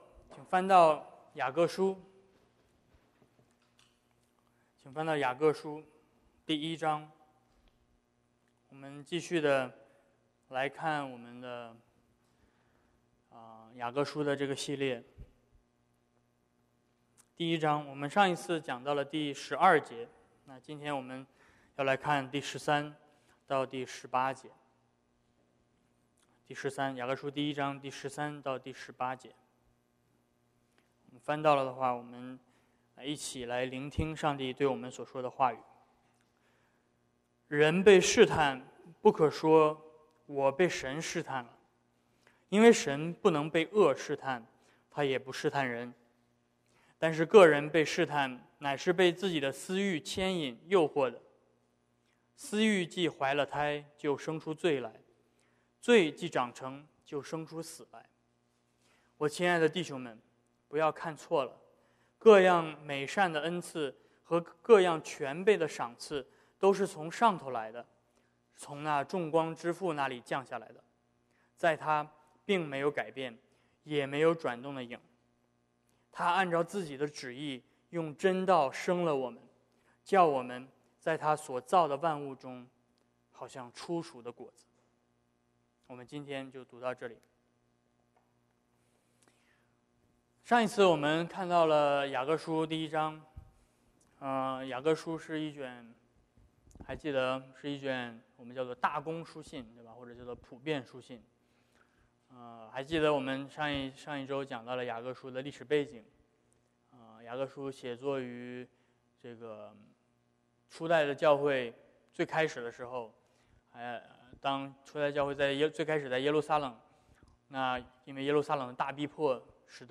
James 1:19-27 Service Type: Sermons 雅各书系列 两种出生 September 8